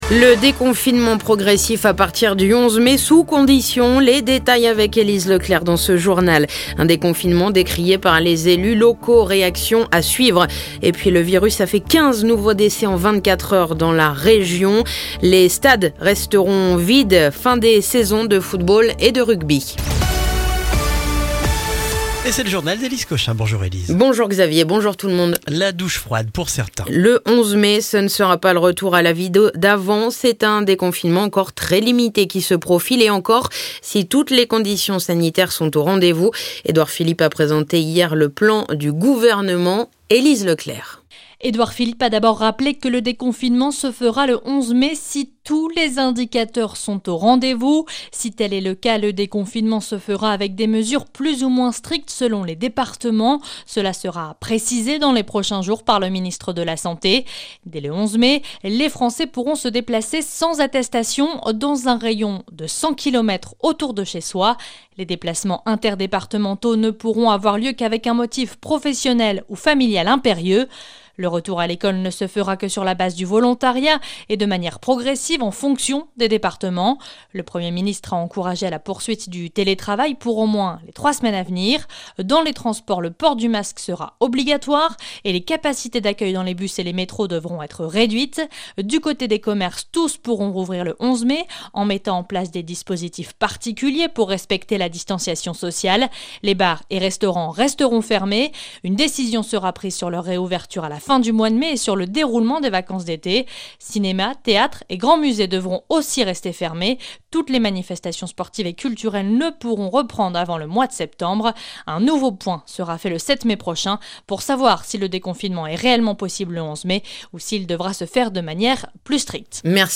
Le journal du mercredi 29 avril